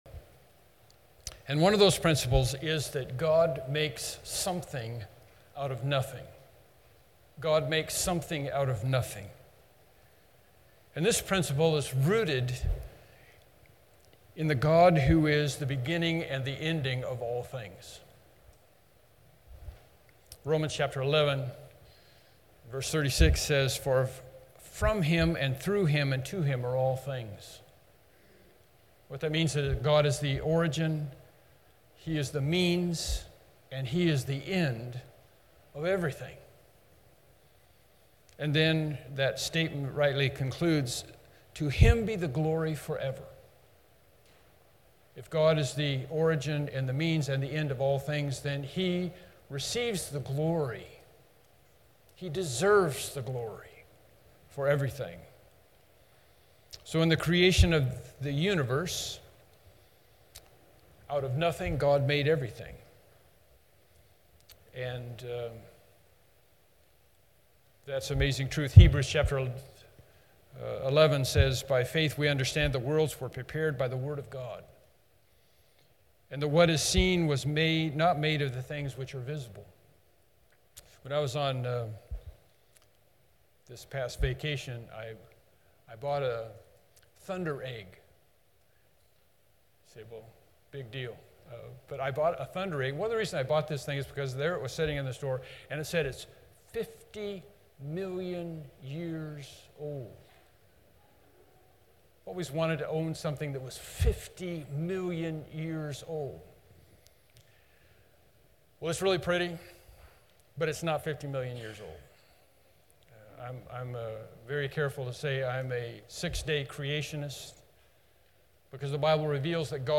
Passage: Mark 3:13-19 Service Type: Morning Worship Service « God is and He Has Spoke